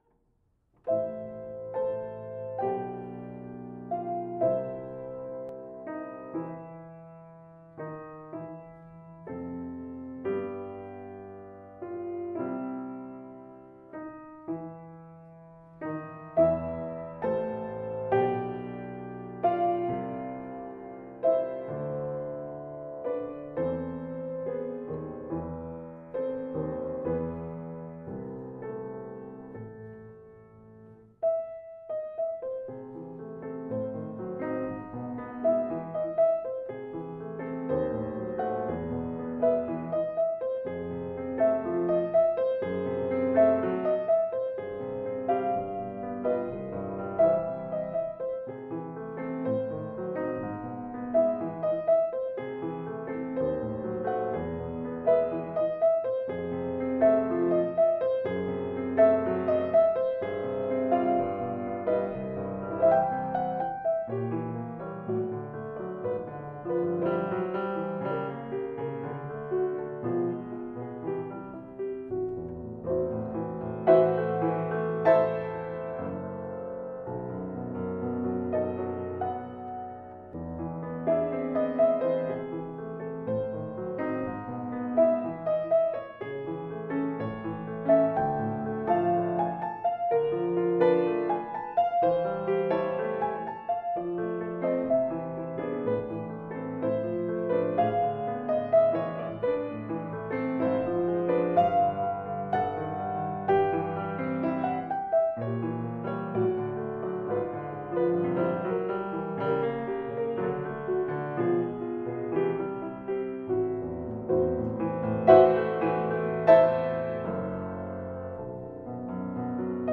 Piano  (View more Intermediate Piano Music)
Classical (View more Classical Piano Music)